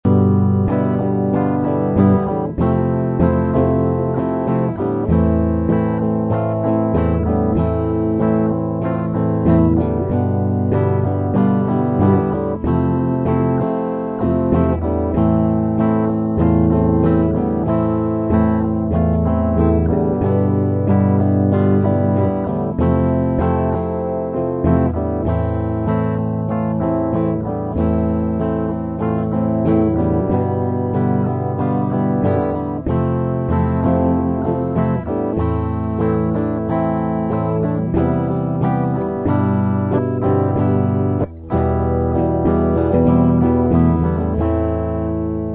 mp3 - припев